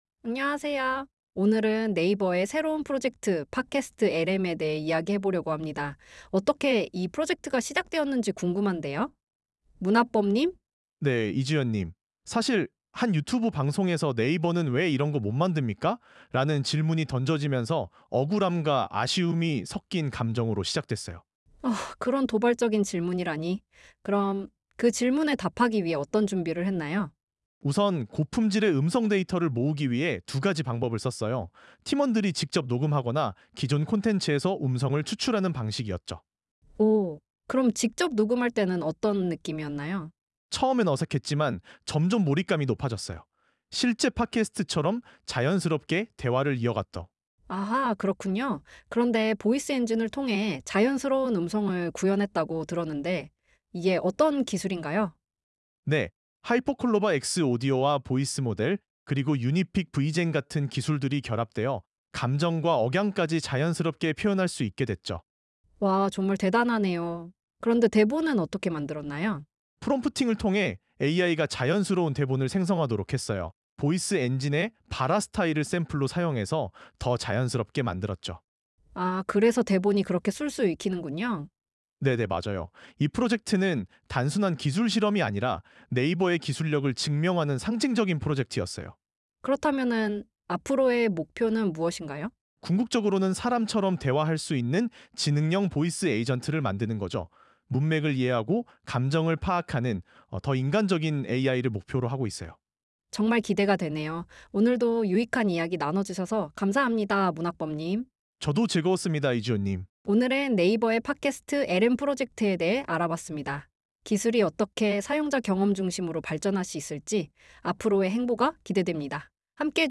PodcastLM 의 주인공 격인 Voice Engine 은 감정 , 숨소리 , 억양까지 자연스럽게 표현할 수 있는 고도화된 TTS (Text-to-speech) 모델입니다 .